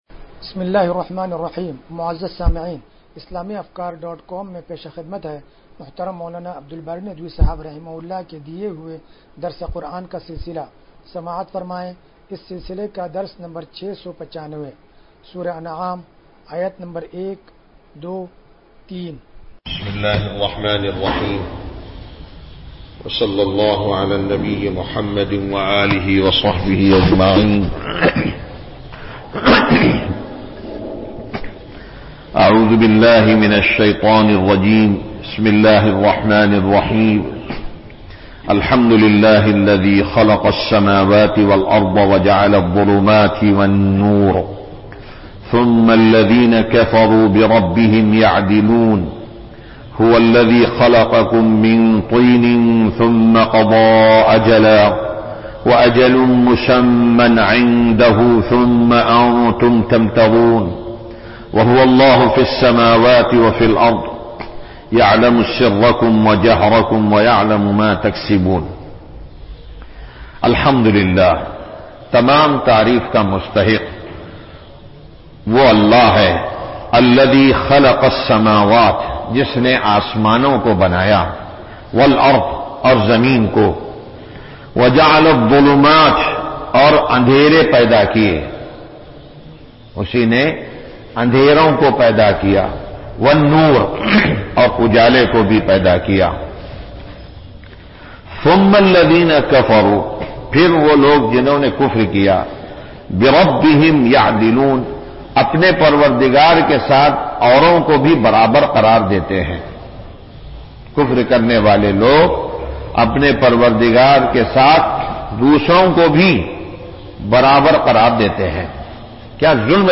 درس قرآن نمبر 0695